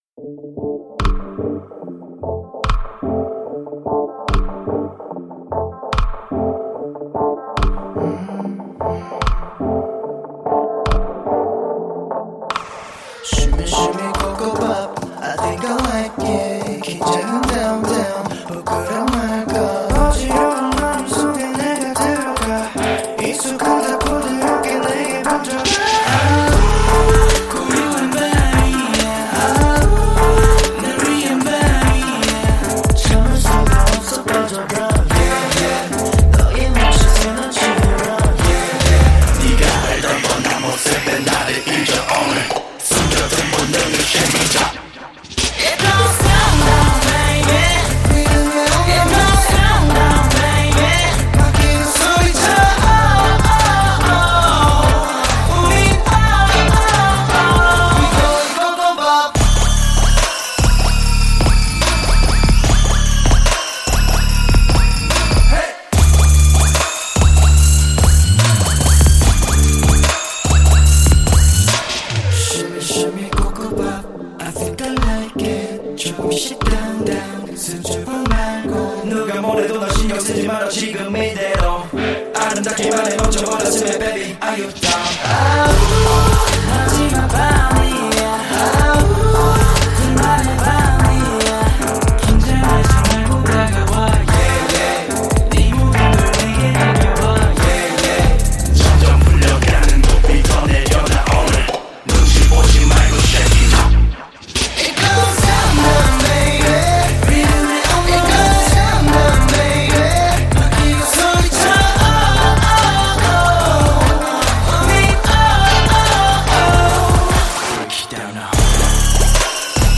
8- موزیک سه بعدی خارجی بیس دار